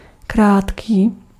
Ääntäminen
IPA: /kuʁ/